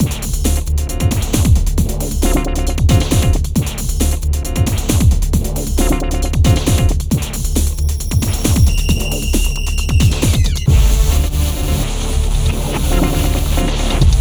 31 Futurefunk-c.wav